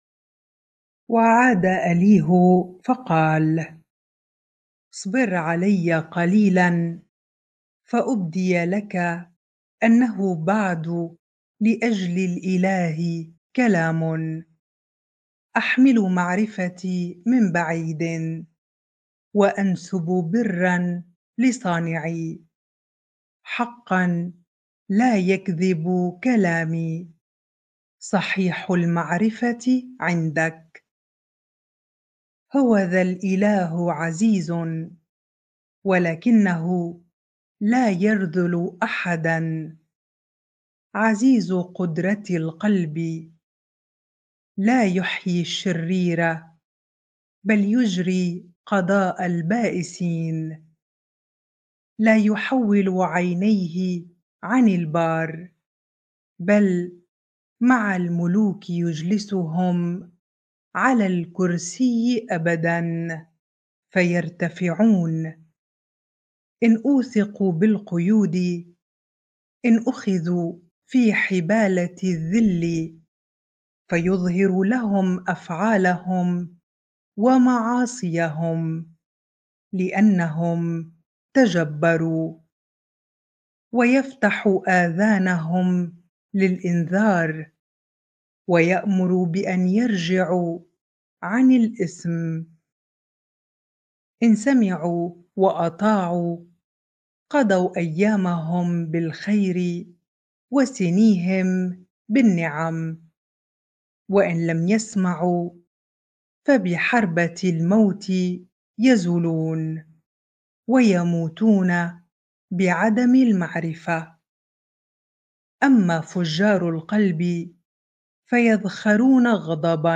bible-reading-Job 36 ar